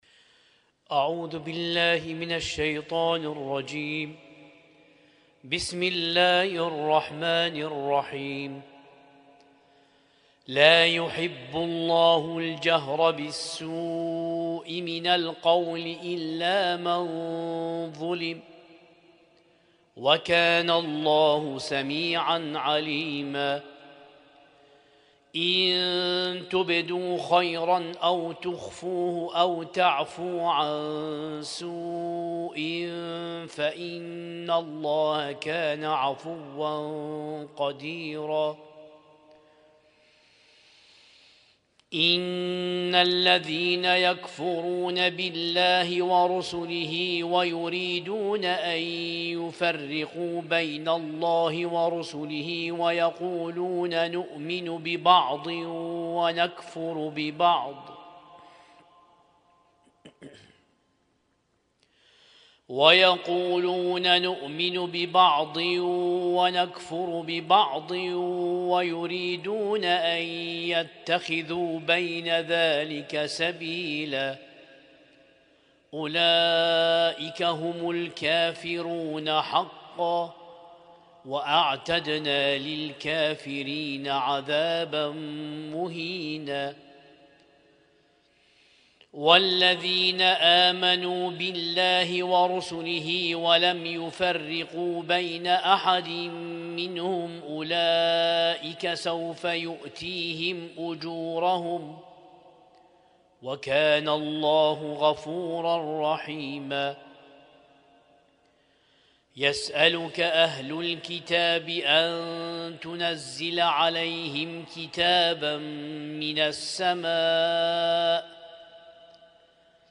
القارئ
اسم التصنيف: المـكتبة الصــوتيه >> القرآن الكريم >> القرآن الكريم 1447